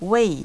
Weiway!Northern: 389-534 CE